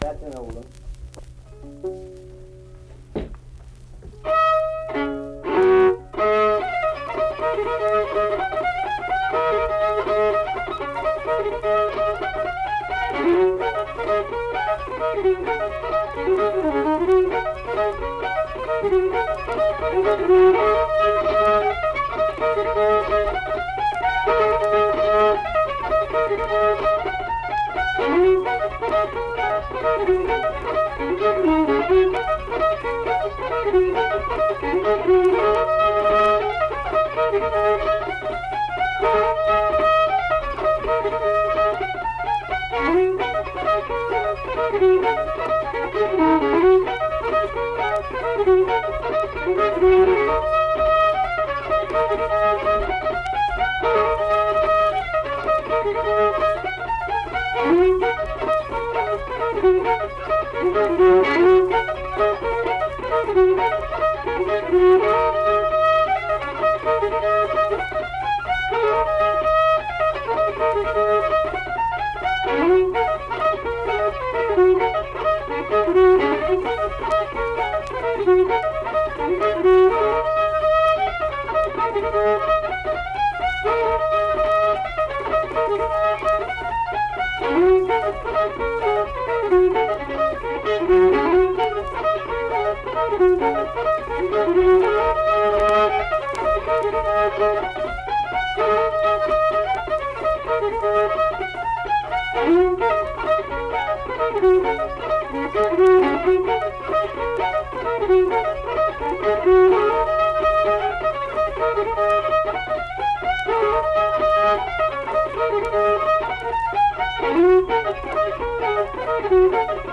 Unaccompanied fiddle music
Instrumental Music
Fiddle
Flatwoods (Braxton County, W. Va.), Braxton County (W. Va.)